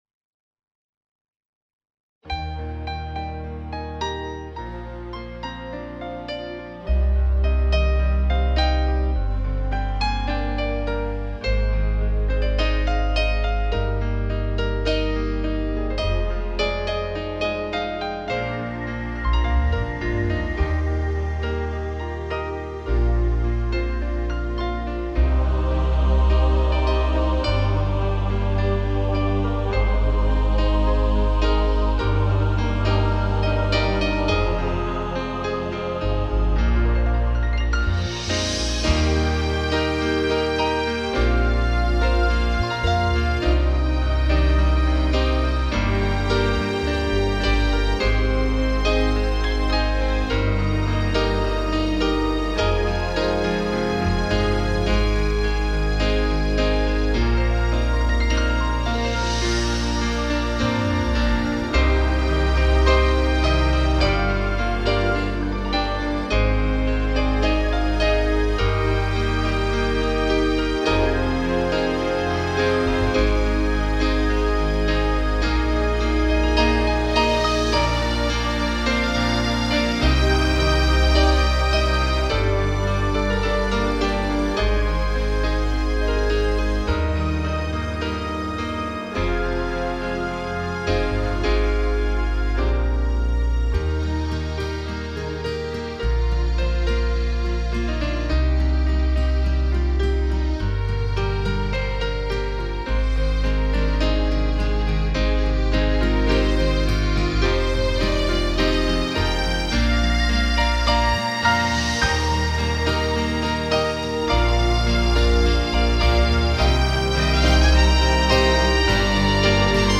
Cor mixt
SUNT-PRIETENUL-IMPARATULUI-negativ.mp3